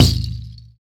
SI2 RATTL03L.wav